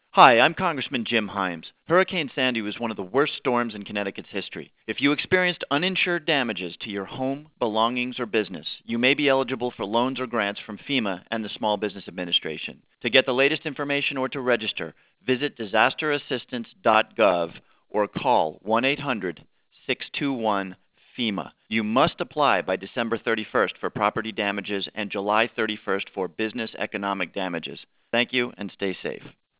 Himes Records PSA to Share Disaster Assistance Info
In the PSA, Himes warns constituents of the fast-approaching deadline and urges anyone who experienced uninsured losses to apply for help.